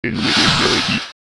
invisibility.ogg